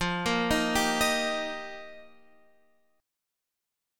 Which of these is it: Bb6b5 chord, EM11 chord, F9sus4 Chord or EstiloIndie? F9sus4 Chord